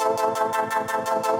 Index of /musicradar/shimmer-and-sparkle-samples/170bpm
SaS_MovingPad02_170-A.wav